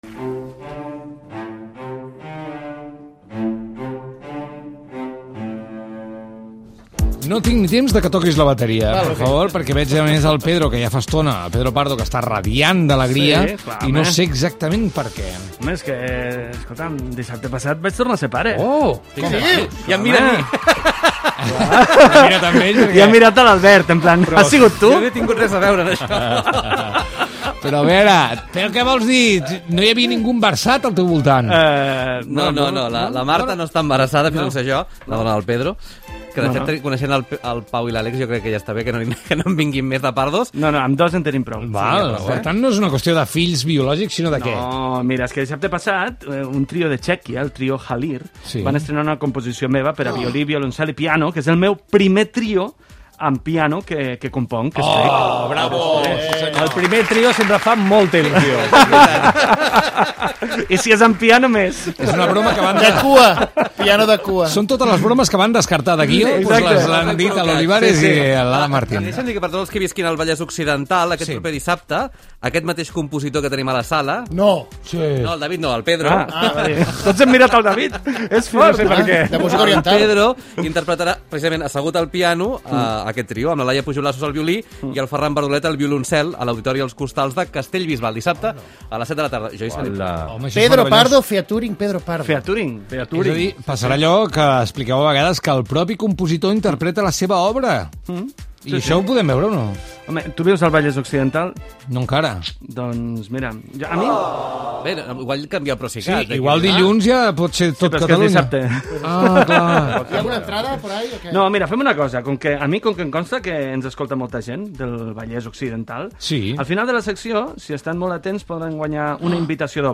Gènere radiofònic Entreteniment